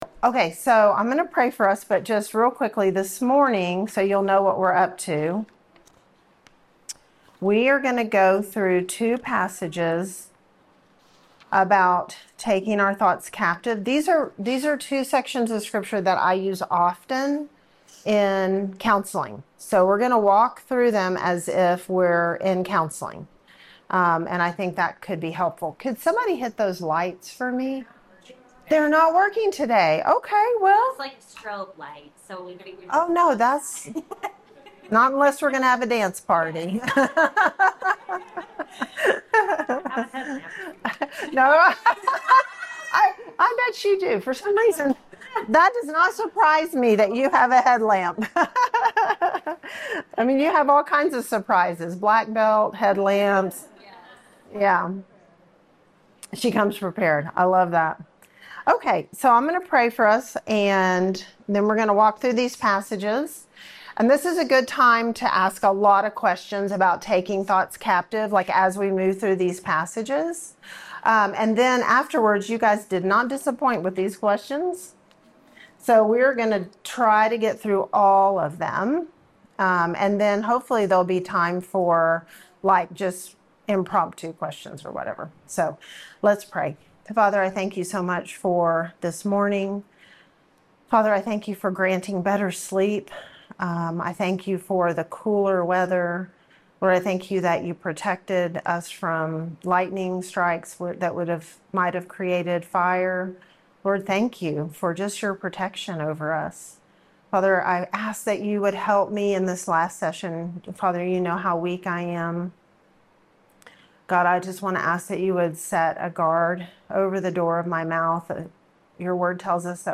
Women's Ministry Guest Speaker